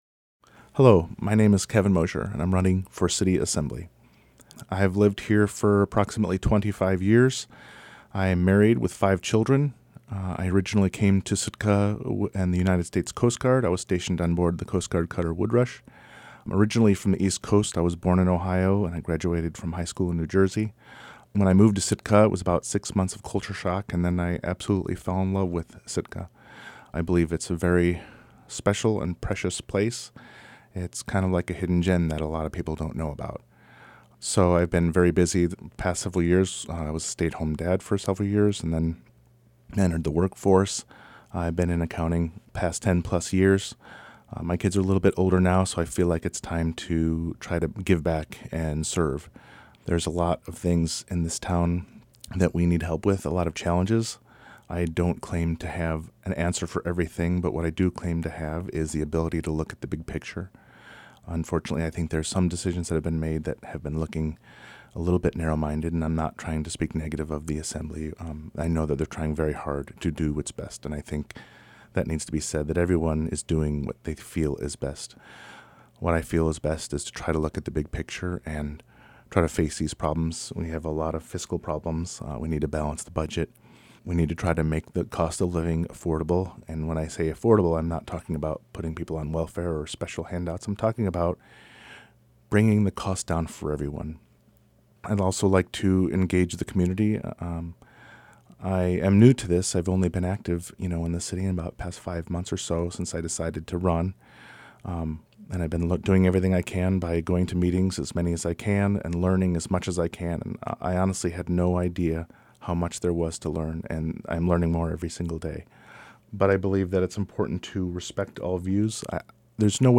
Candidate Statement for Sitka Assembly